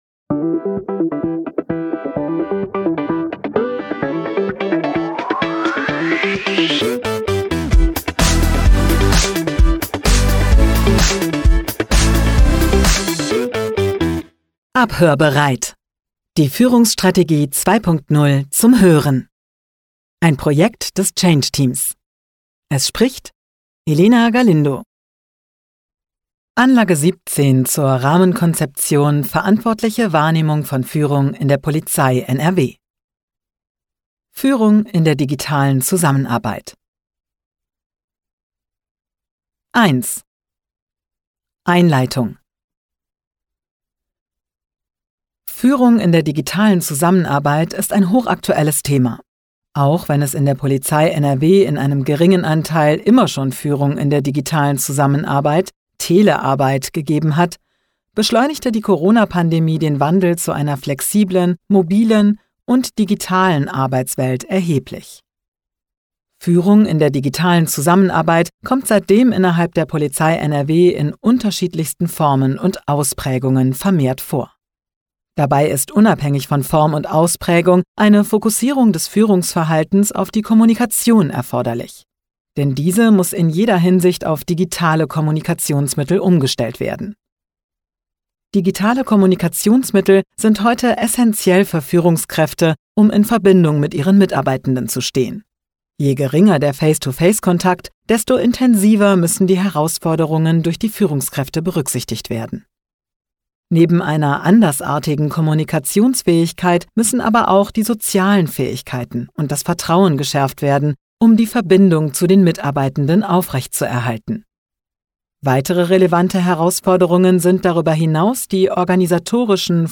Die Führungsstrategie der Polizei Nordrhein-Westfalen jetzt auch als Hörbuch – Es gibt was zu hören.